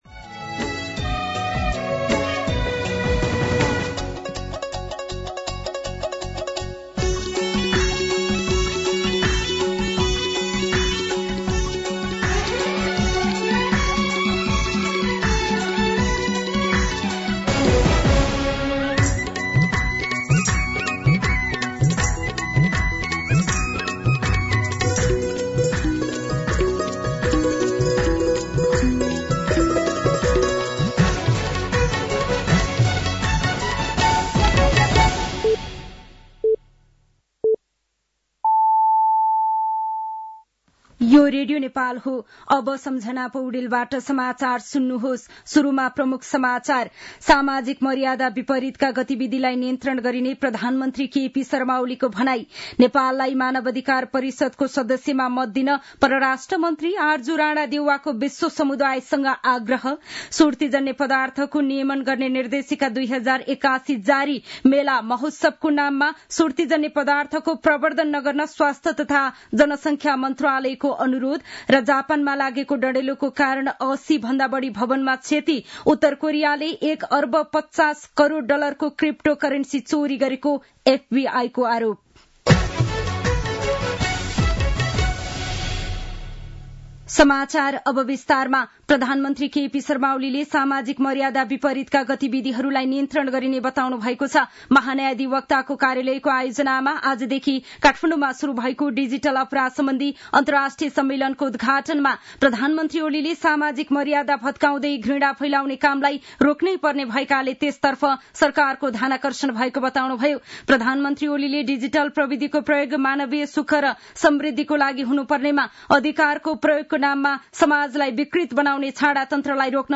दिउँसो ३ बजेको नेपाली समाचार : १६ फागुन , २०८१